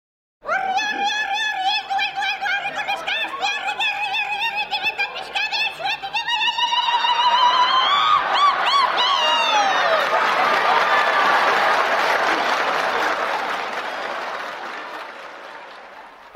Irrintzia
Irrintzia2.mp3